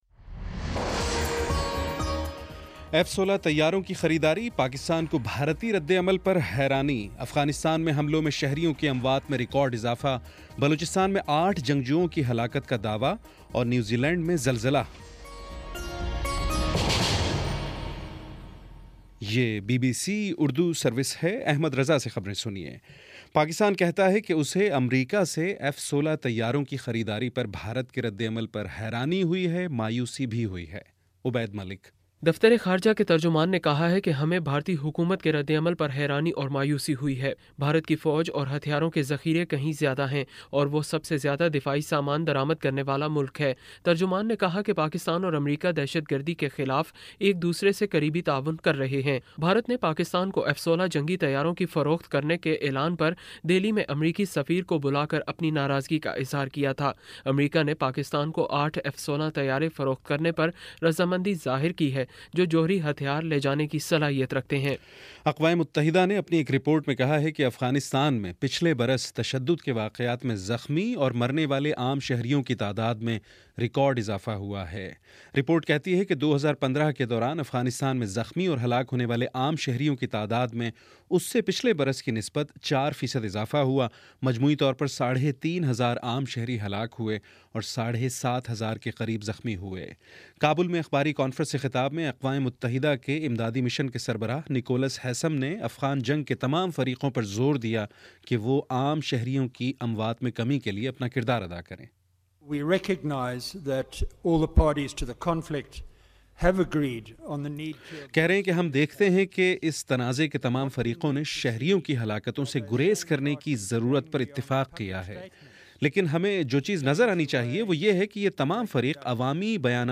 فروری 14 : شام چھ بجے کا نیوز بُلیٹن